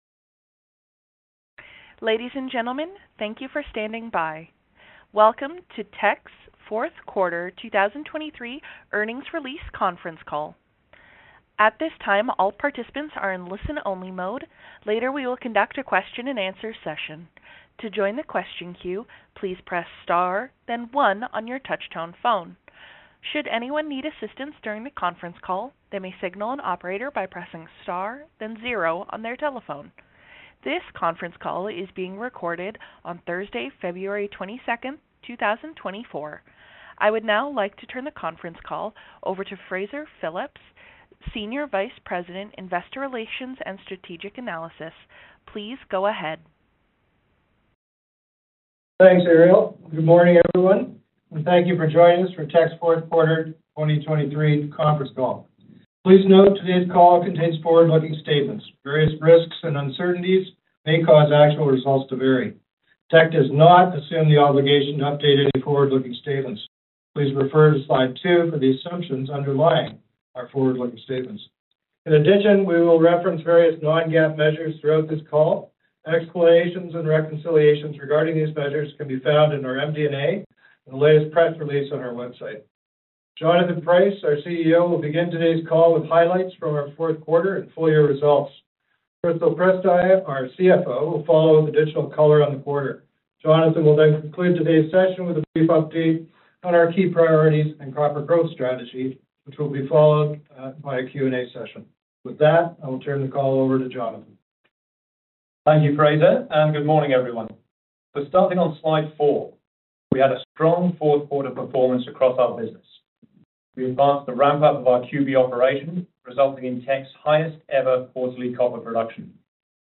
Q4 2023 Conference Call